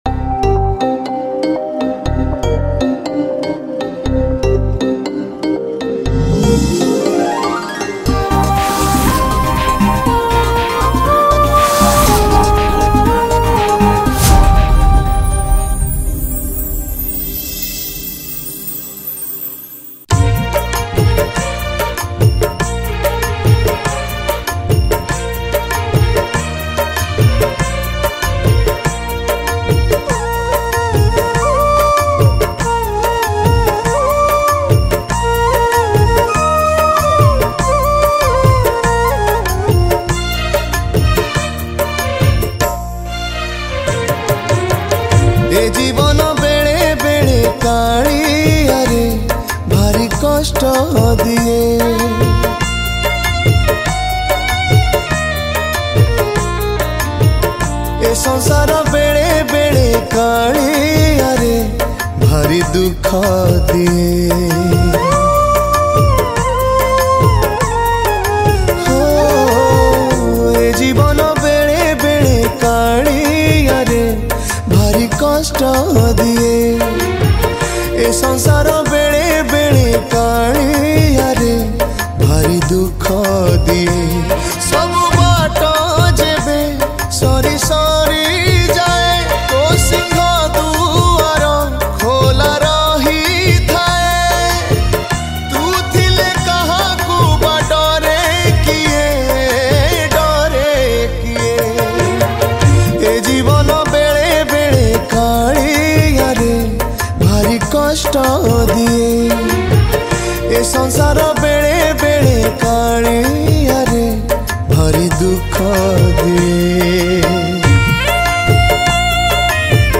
Ratha Yatra Odia Bhajan 2023 Songs Download